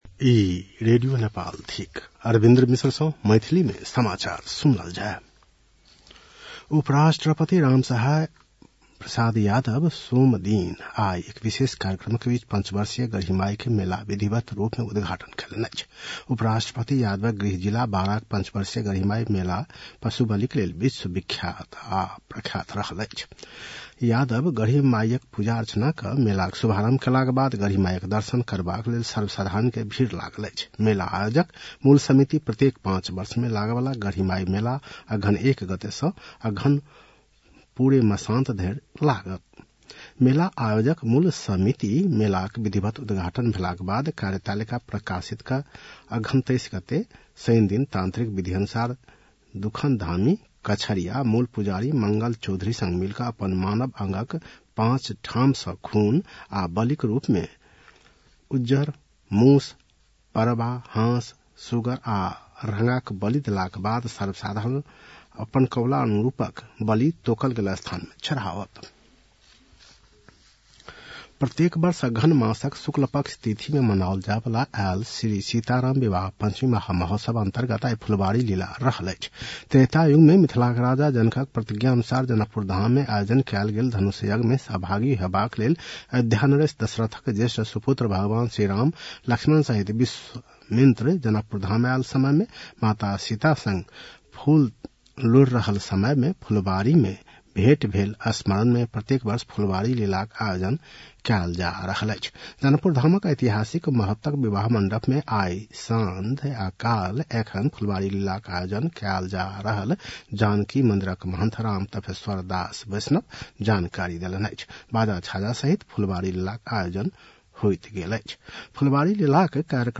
मैथिली भाषामा समाचार : १८ मंसिर , २०८१